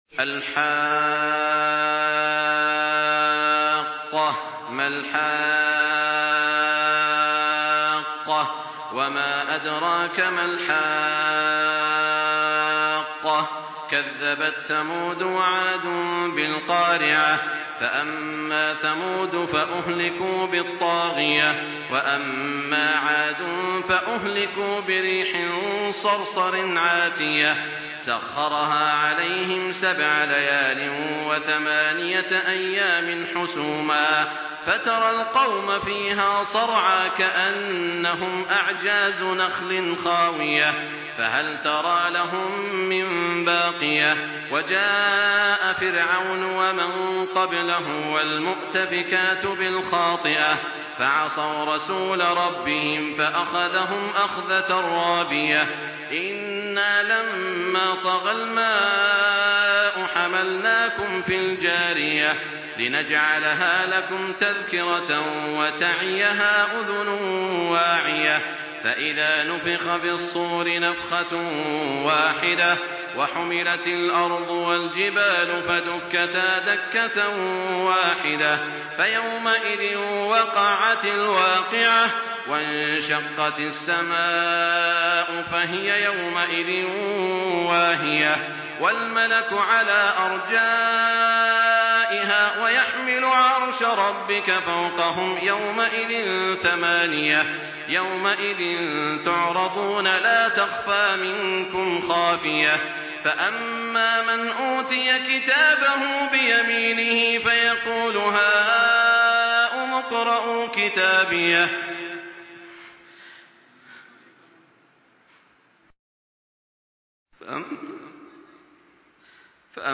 Surah Al-Haqqah MP3 in the Voice of Makkah Taraweeh 1424 in Hafs Narration
Murattal